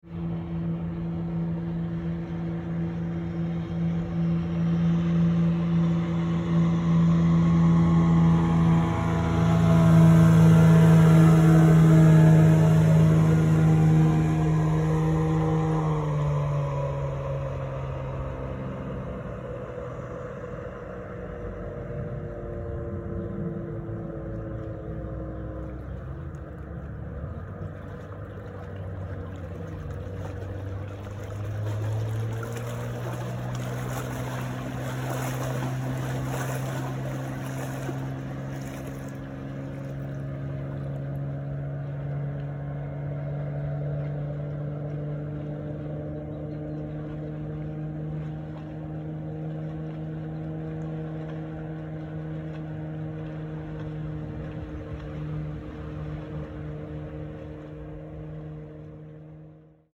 Звуки лодки, катера и яхты скачать
Моторная лодка проезжает мимо, шум поднятых волн
motor-lodka-w.mp3